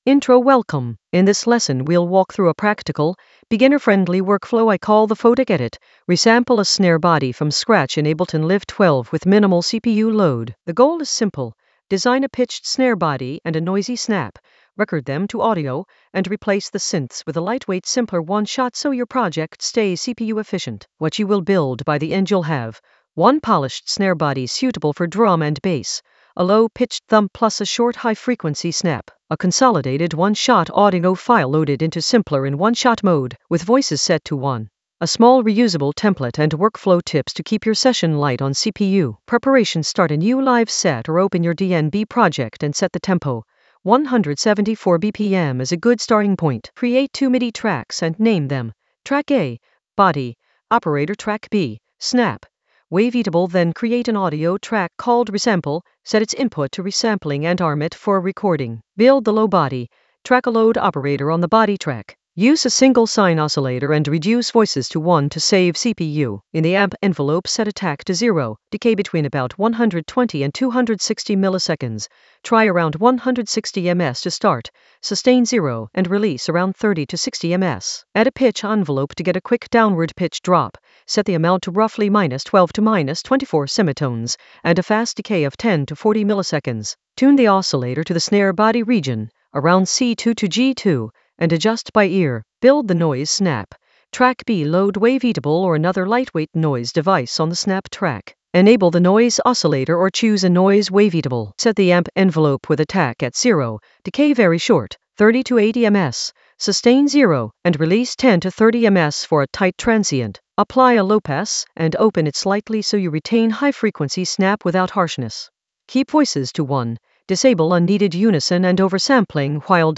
An AI-generated beginner Ableton lesson focused on Photek edit: resample a snare body from scratch in Ableton Live 12 with minimal CPU load in the Workflow area of drum and bass production.
Narrated lesson audio
The voice track includes the tutorial plus extra teacher commentary.